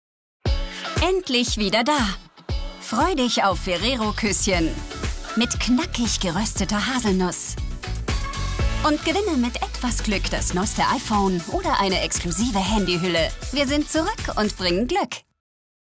dunkel, sonor, souverän, sehr variabel
Mittel minus (25-45)
Commercial (Werbung), Off